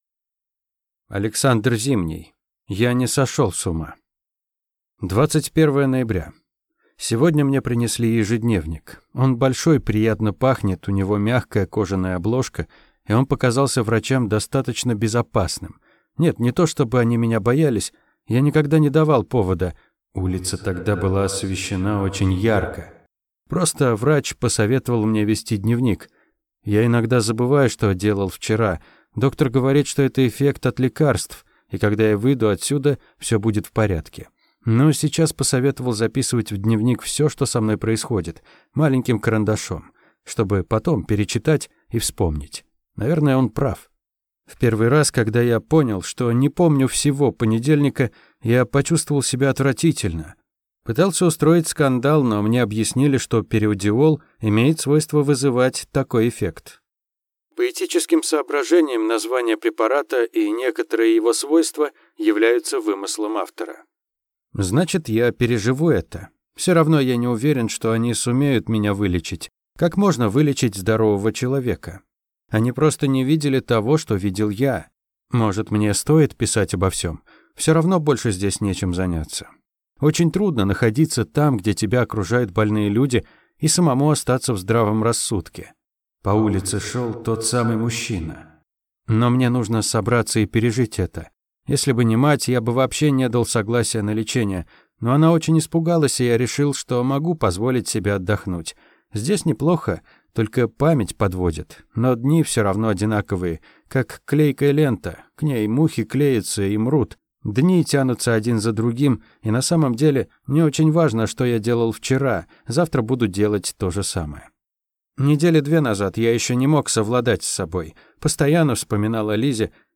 Аудиокнига Я не сошел с ума | Библиотека аудиокниг